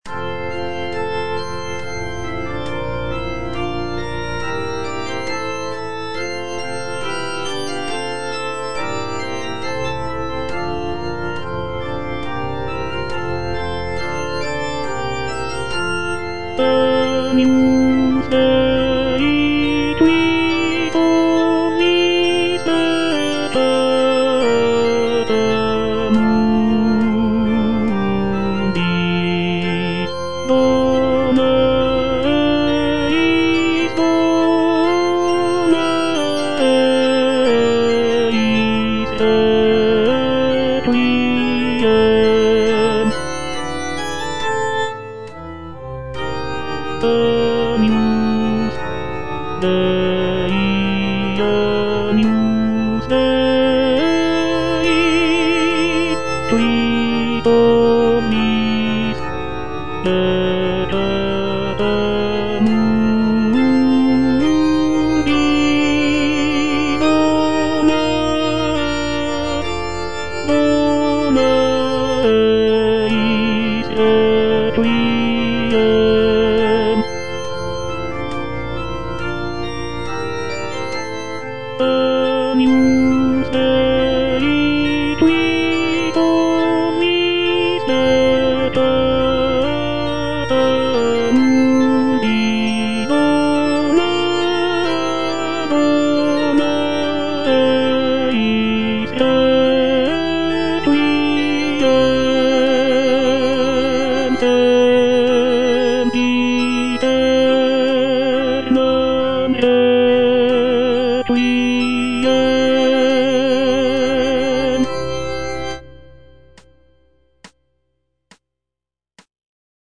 version with a smaller orchestra
tenor II) (Voice with metronome